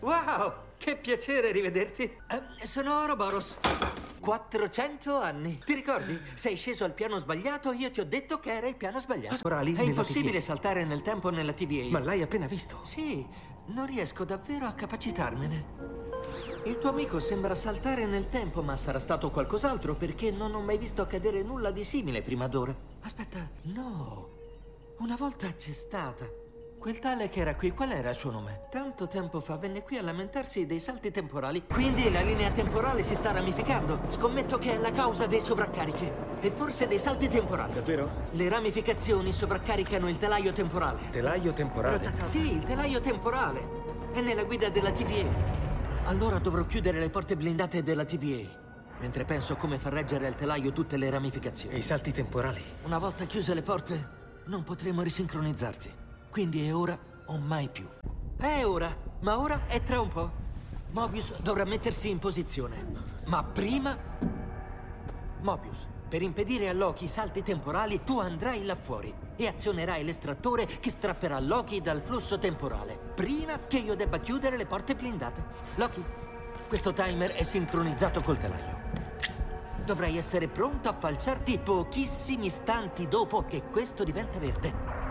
nel telefilm "Loki", in cui doppia Ke Huy Quan.